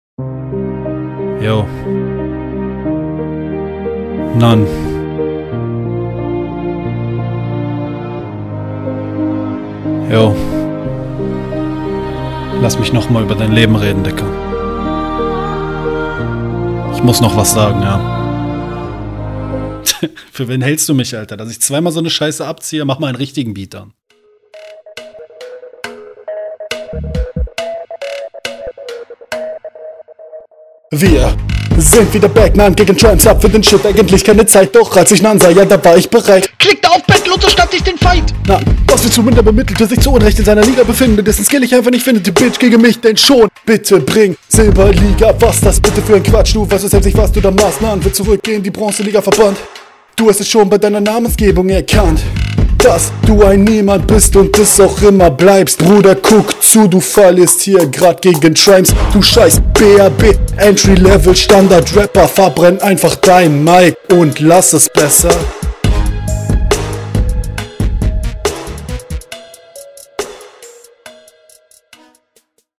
Mies übersteuernd, sonst flow auch noch nicht ausgereift.